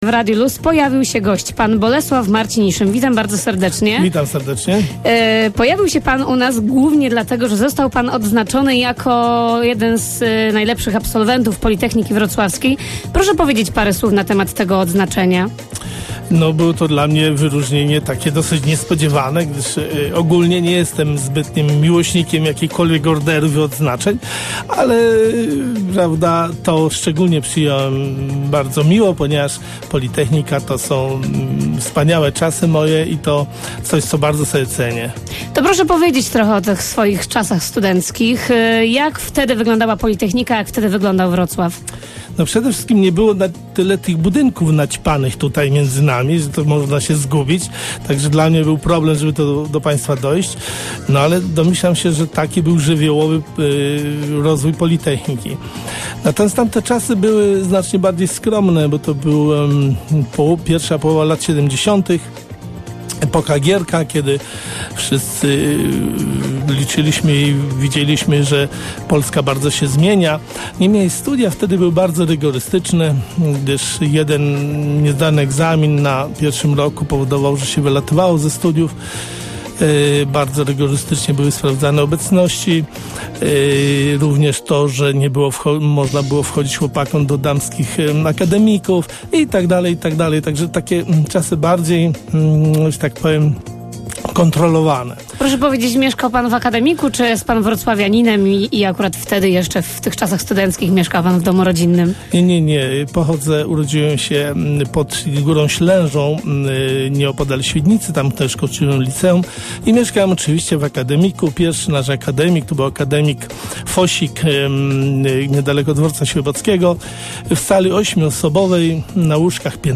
Wywiad dla akademickiego Radia LUZ